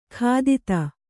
♪ khādita